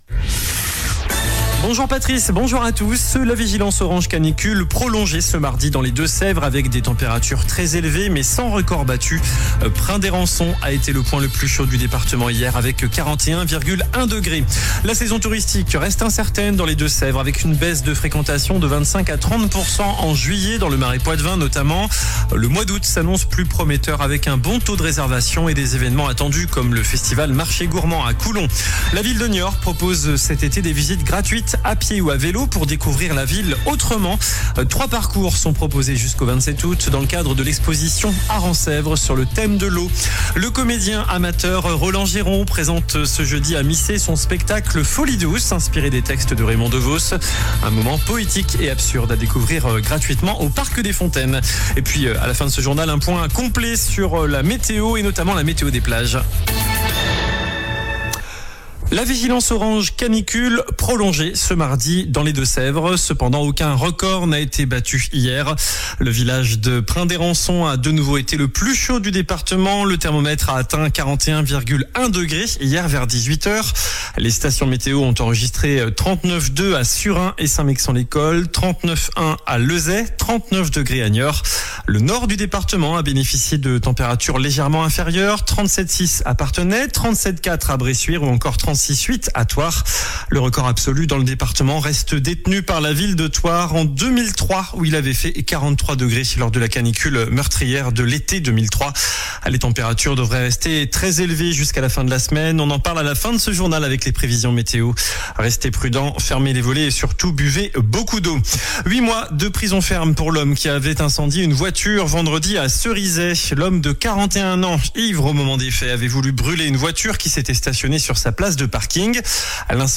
JOURNAL DU MARDI 12 AOÛT ( MIDI )